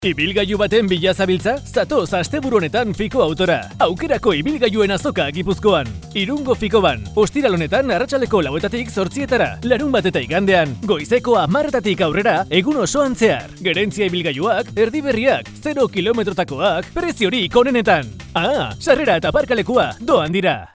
locutor euskera, basque voice over